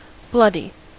Ääntäminen
British: IPA : /ˈblʌ.di/